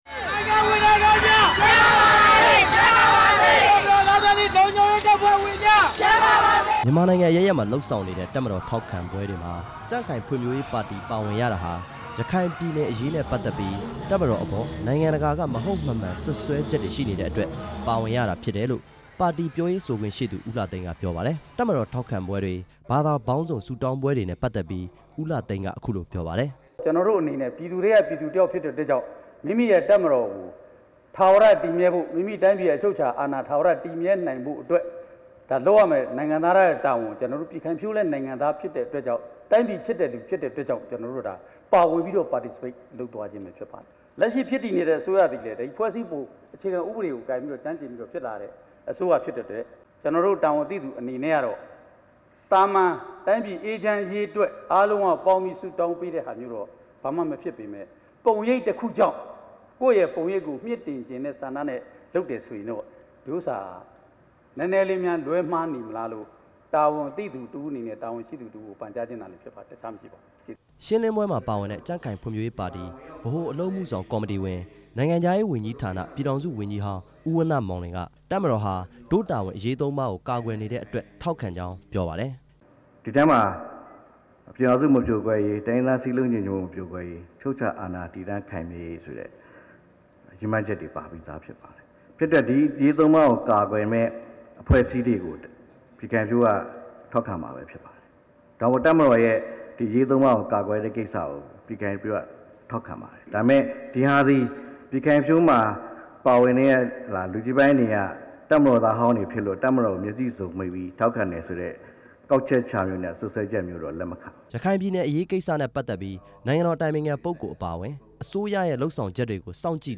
ကြံ့ခိုင်ရေးနဲ့ ဖံ့ွဖြိုးရေးပါတီ သတင်းစာရှင်းလင်းပွဲ